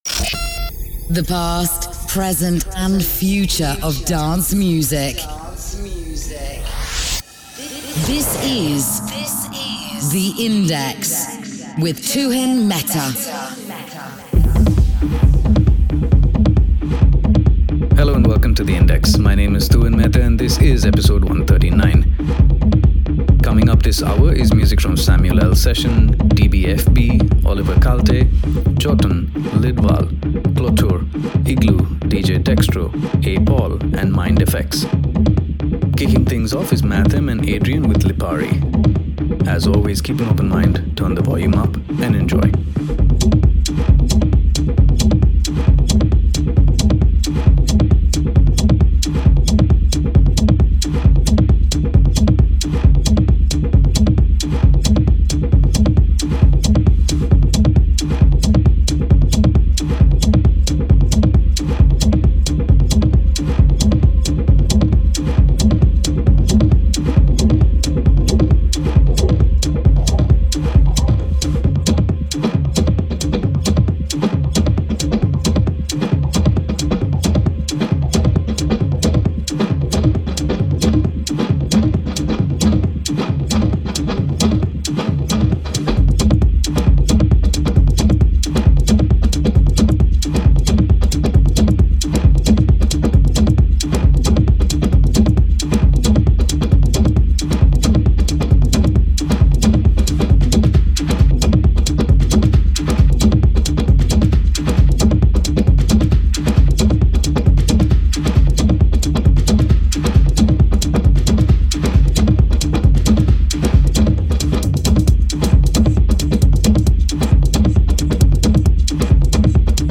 The past, present & future of dance music
Techno